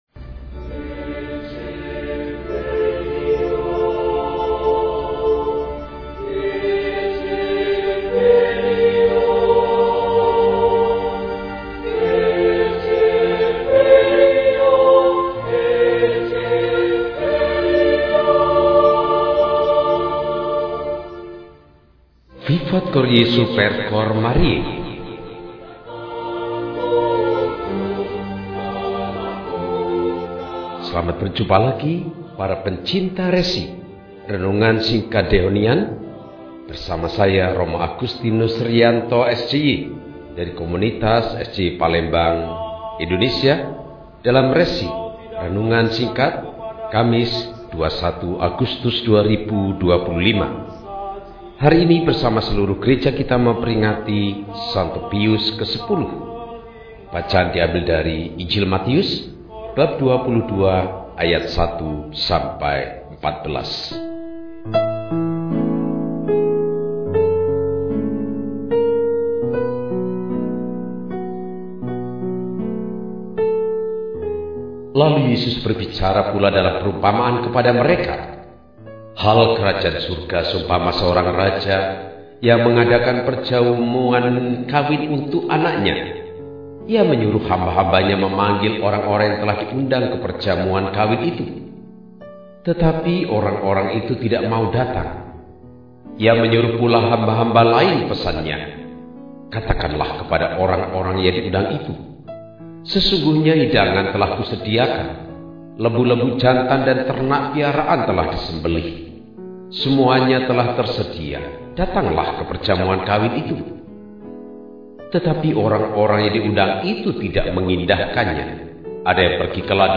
Kamis, 21 Agustus 2025 – Peringatan St. Pius X, Paus – RESI (Renungan Singkat) DEHONIAN